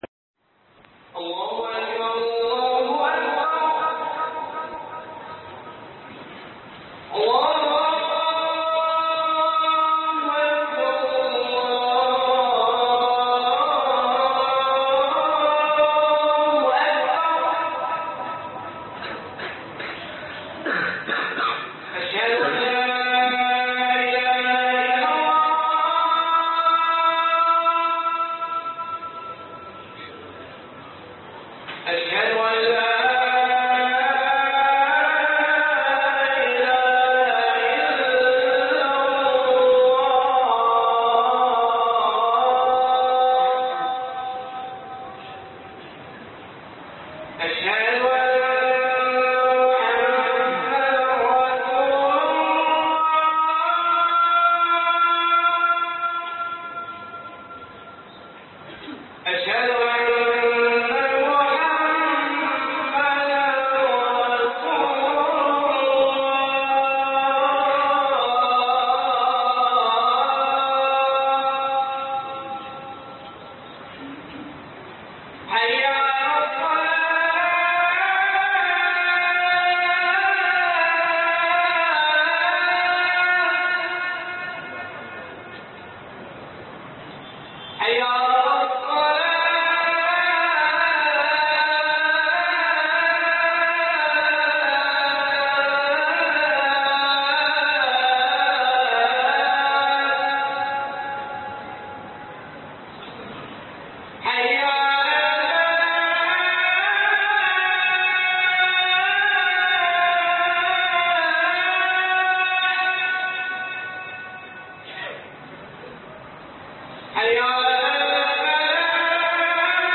تكلم حتى أراك - خطب الجمعة